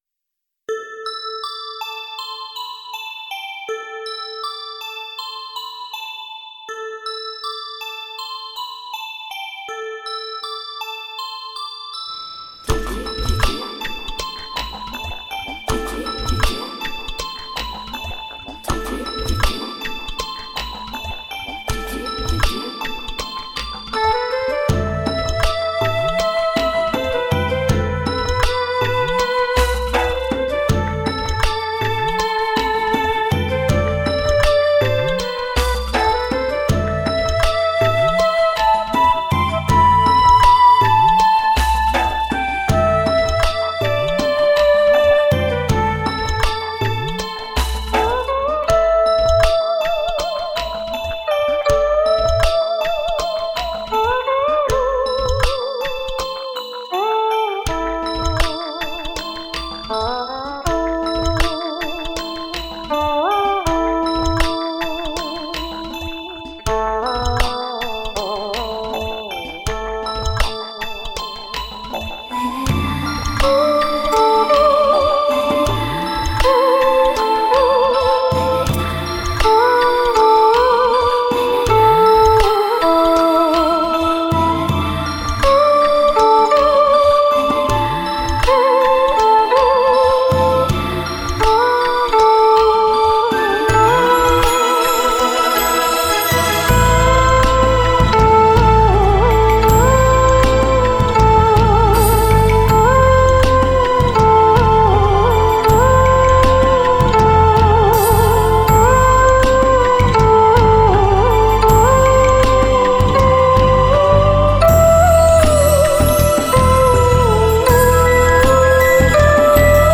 乍听虚幻缥缈，了无边际；细品平实自然，音律井然。